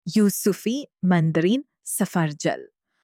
tangerine-in-arabic.mp3